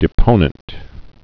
(dĭ-pōnənt)